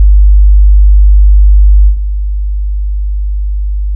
《基準壁》からの音 2秒 →《基準壁+ノイズクリア》からの音 2秒
- 固体伝播音の場合 -
( スピーカーを壁に直付け固定して測定 )